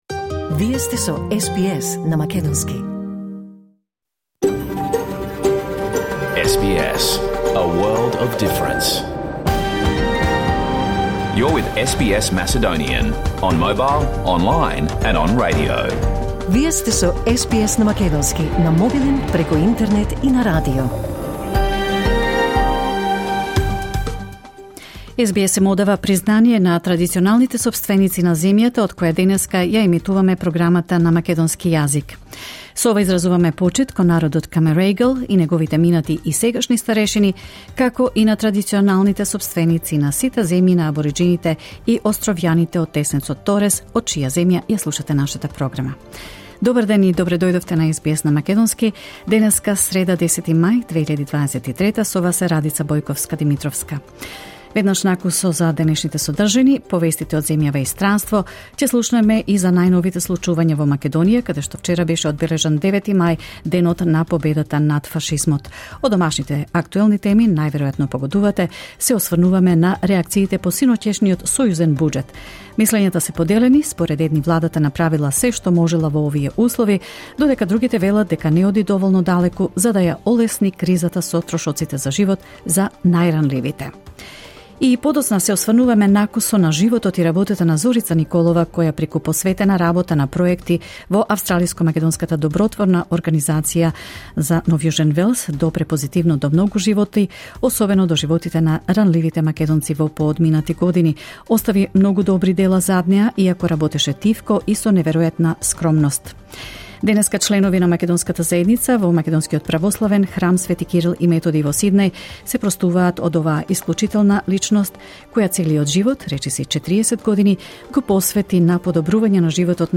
SBS Macedonian Live on Air on 9 May 2023